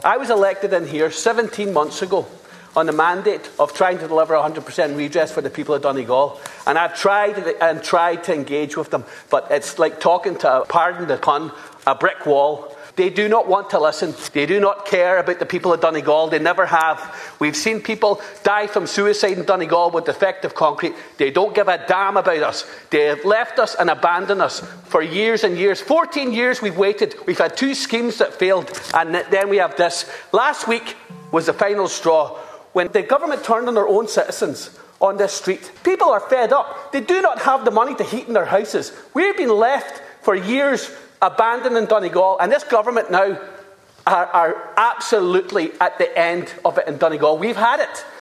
Speaking during last evening’s confidence motion, which the government won by 92 votes to 78, 100% Redress Deputy Charles Ward said there is a sense in the county that the people have been abandoned.